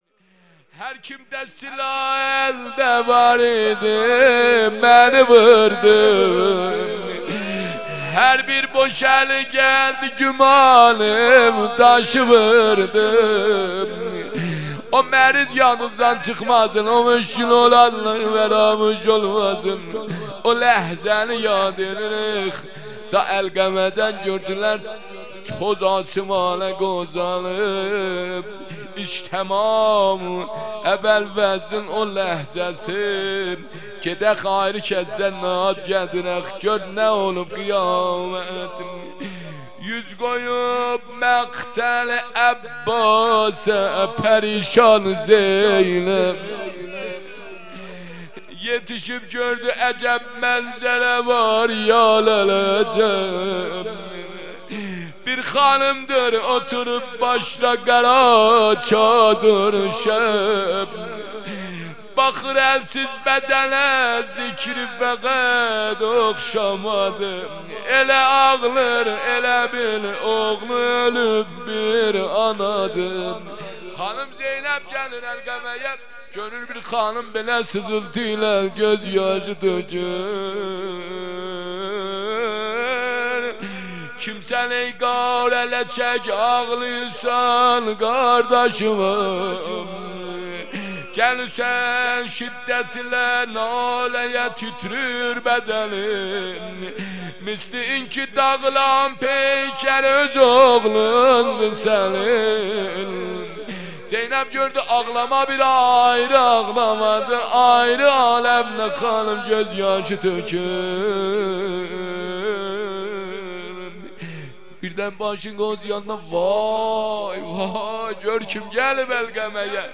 مراسم هفتگی | 3 آبان ماه 1400